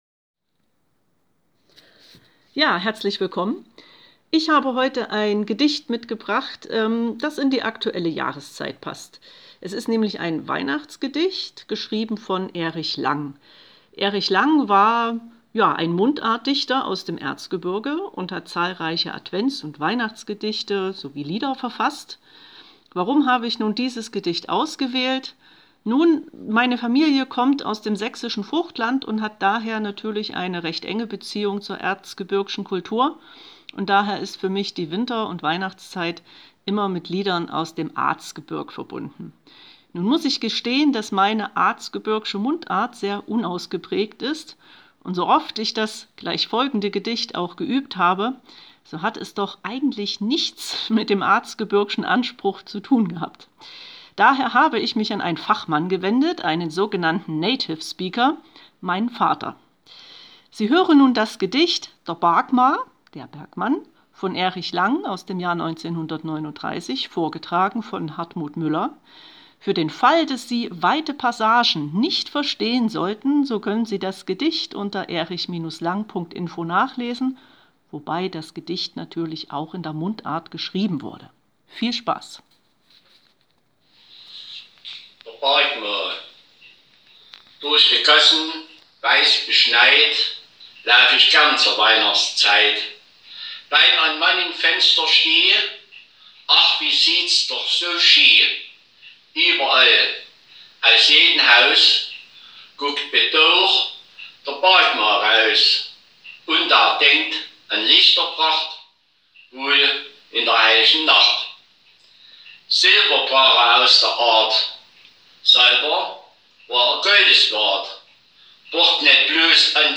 Wir haben Gedichte aufgenommen, die wir aus dem ein oder anderen Grund mögen, und Sie können sich unsere Aufnahmen anhören, an jedem Tag bis Weihnachten eine andere.